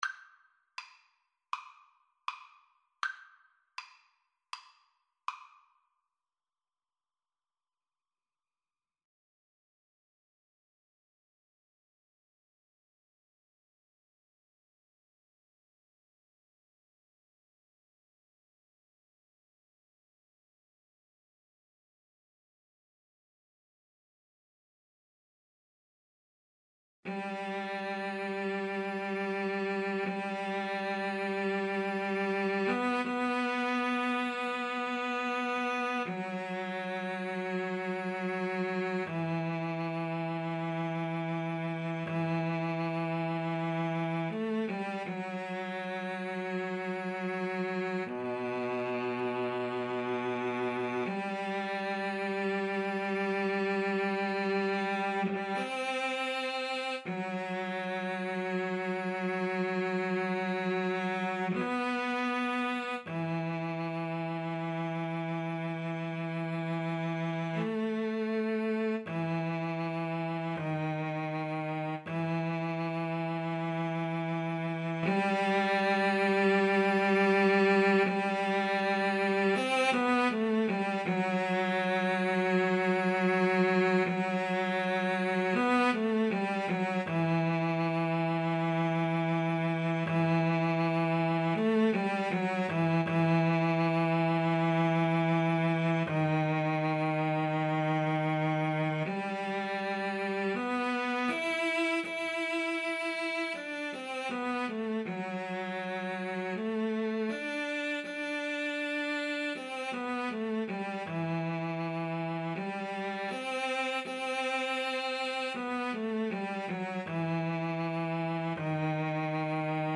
Andante
4/4 (View more 4/4 Music)
Classical (View more Classical Guitar-Cello Duet Music)